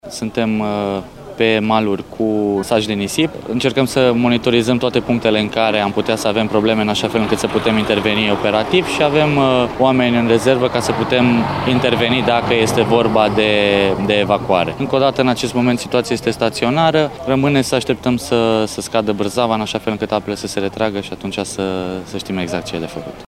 Eugen-Dogariu-alerta-inundatii-Denta.mp3